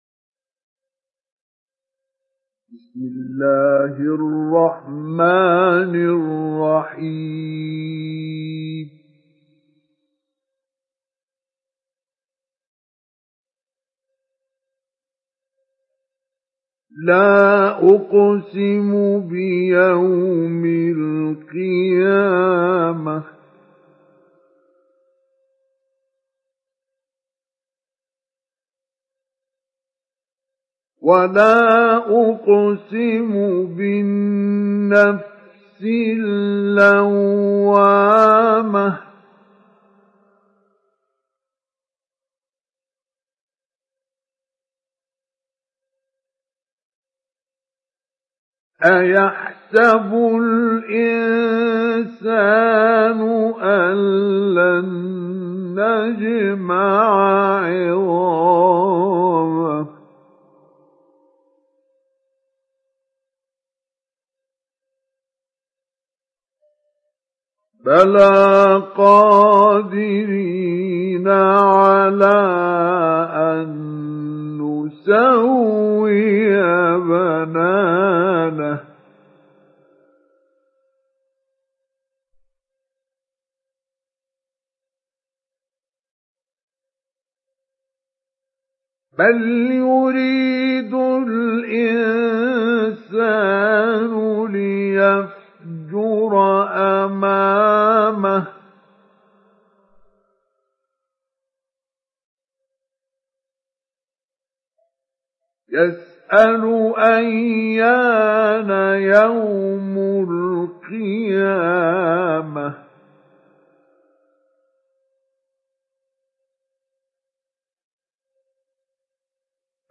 Download Surah Al Qiyamah Mustafa Ismail Mujawwad